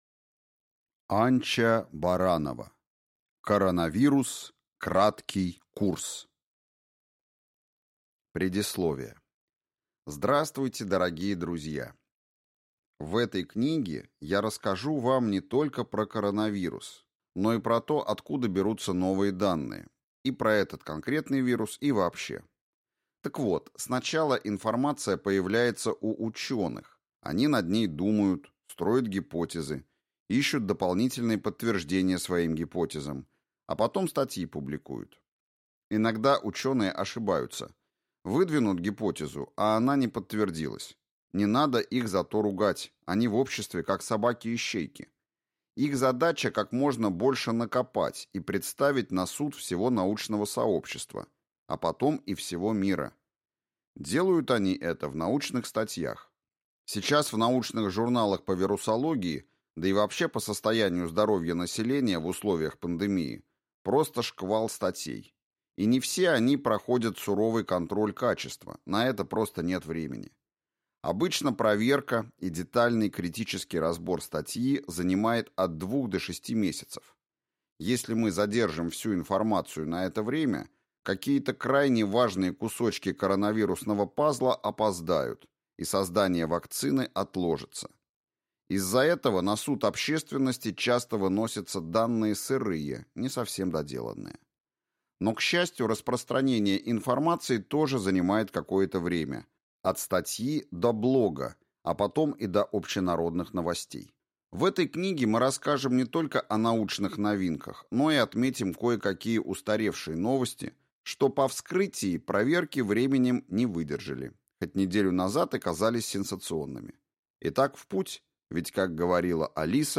Аудиокнига Коронавирус: Краткий курс. Новые данные | Библиотека аудиокниг